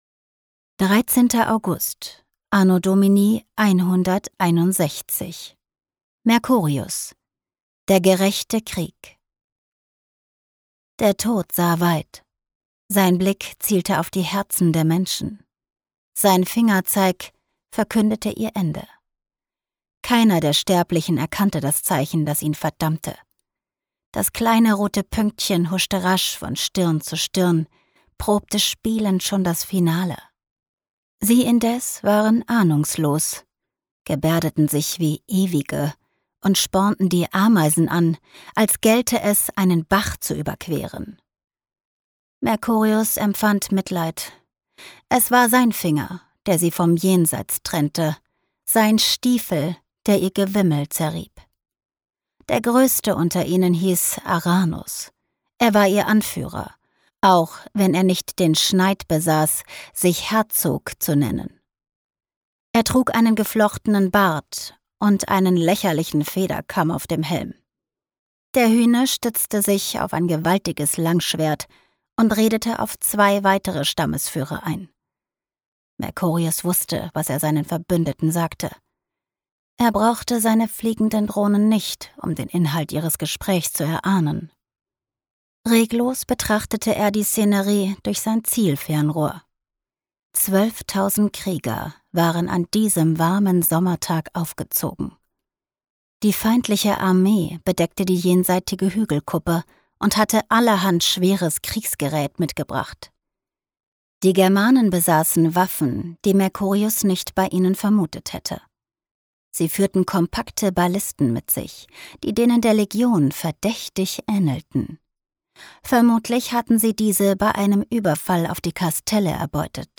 Mit ihrer warmen, wandelbaren Stimme und ihrem Gespür für Emotionen verleiht sie Hörbüchern, Werbespots, Dokumentationen und anderen Projekten eine unverwechselbare Note.
Die-Phoenix-Initiative-Die-Idee-der-Zeit-Band-2-des-Zeitreiseabenteuers-Hoerprobe.mp3